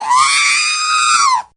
Звуки R2D2
Крик дроида R2-D2